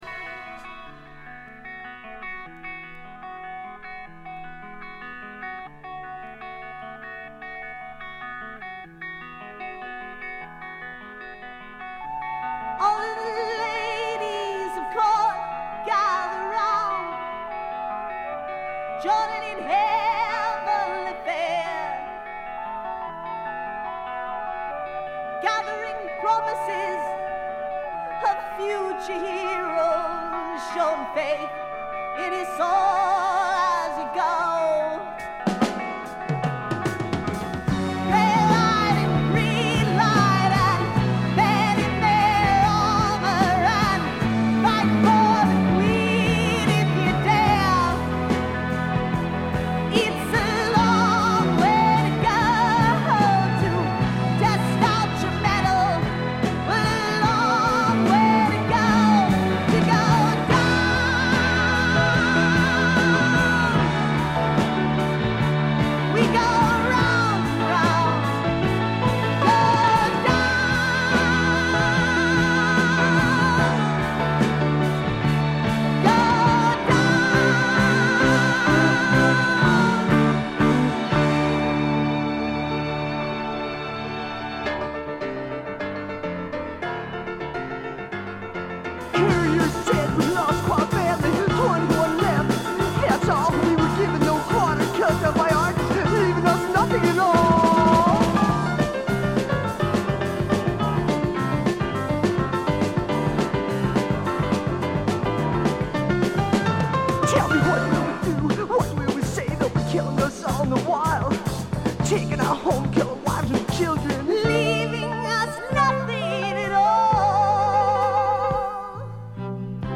ほとんどノイズ感無し。
米国産ハード・プログレッシヴ・ロックの名盤。
忘れてならいのは曲がポップで哀愁味もあってとても良くできていること。
試聴曲は現品からの取り込み音源です。